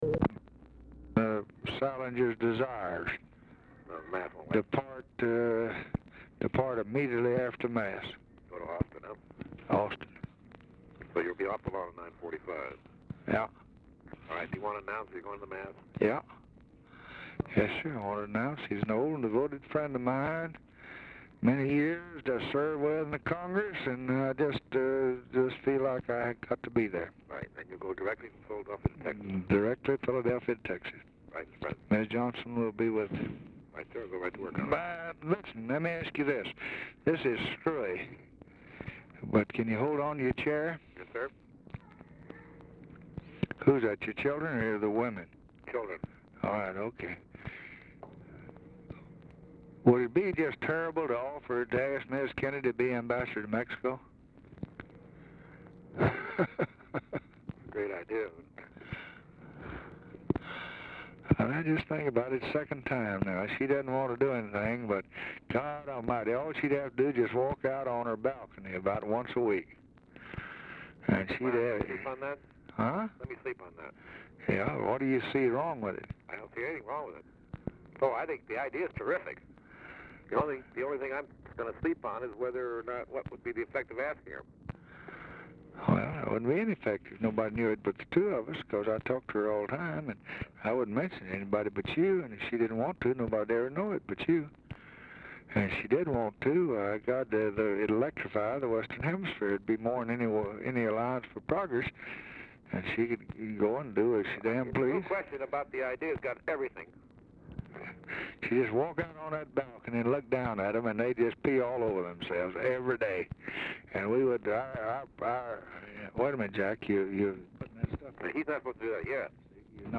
Telephone conversation # 683, sound recording, LBJ and PIERRE SALINGER, 12/23/1963, time unknown | Discover LBJ
RECORDING STARTS AFTER CONVERSATION HAS BEGUN
Format Dictation belt
Location Of Speaker 1 Oval Office or unknown location